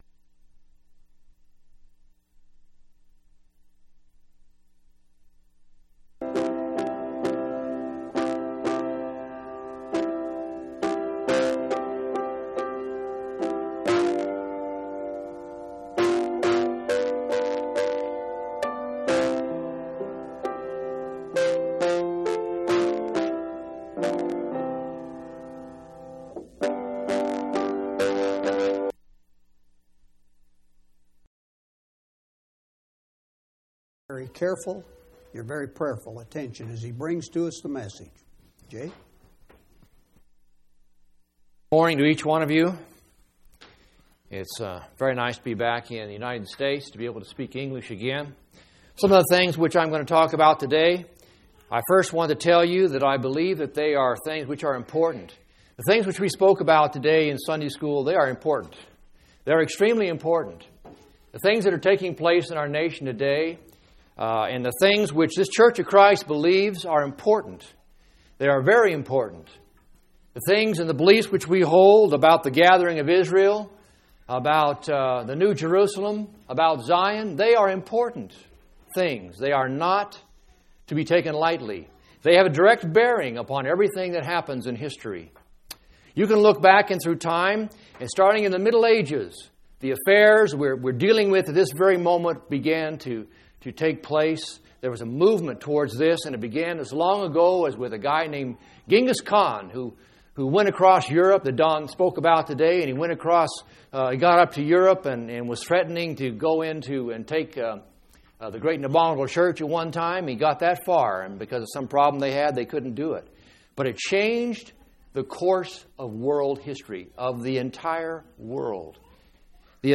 3/16/2003 Location: Phoenix Local Event